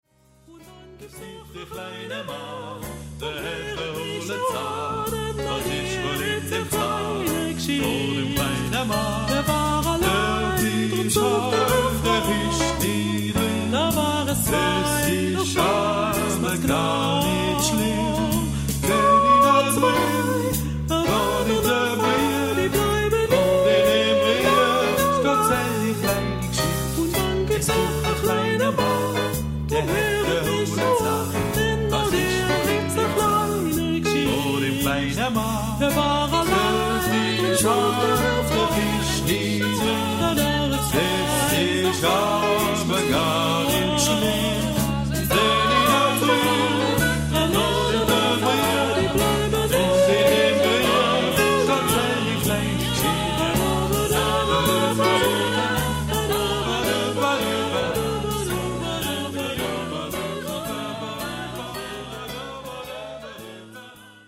Epoque: 20th century
Genre-Style-Form: Canon ; Swing
Type of Choir:  (3 equal voices )
Tonality: A major